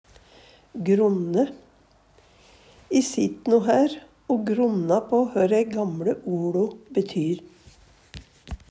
gronne - Numedalsmål (en-US)